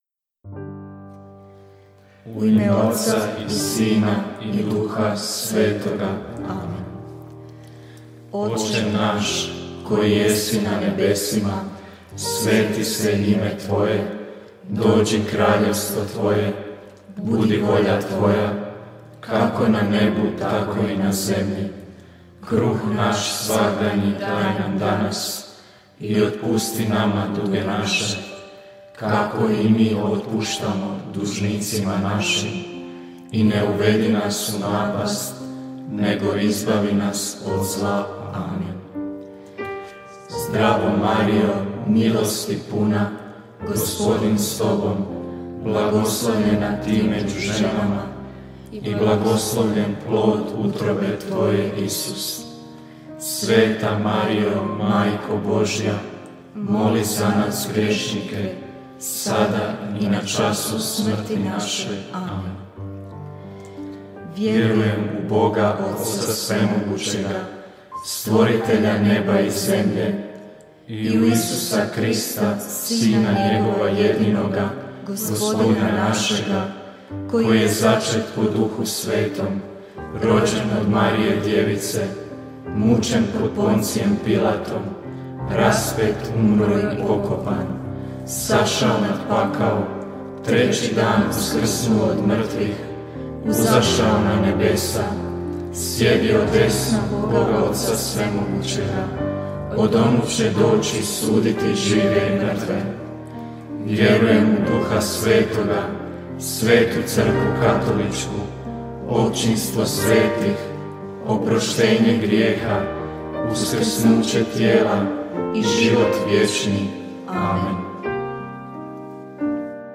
Novi, službeni prijevod krunice Božjeg milosrđa, pjevano.